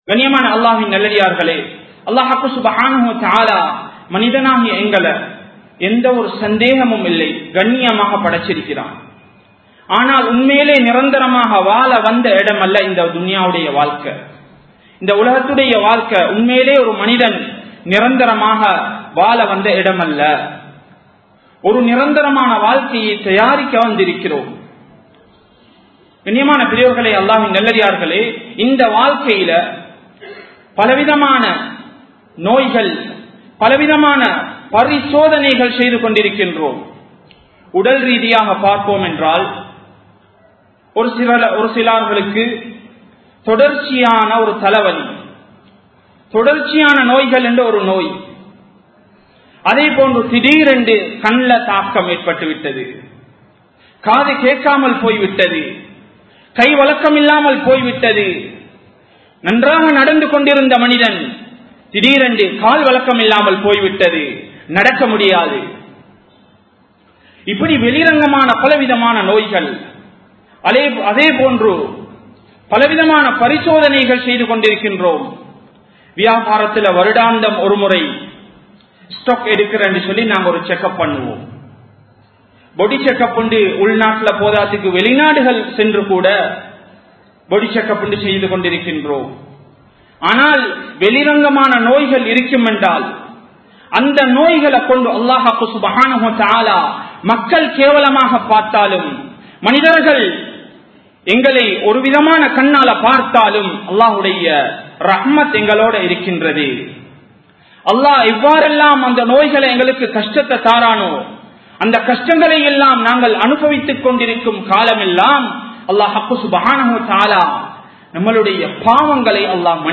Ullaththin Noaihal (உள்ளத்தின் நோய்கள்) | Audio Bayans | All Ceylon Muslim Youth Community | Addalaichenai
Colombo 11, Samman Kottu Jumua Masjith (Red Masjith) 2017-12-22 Tamil Download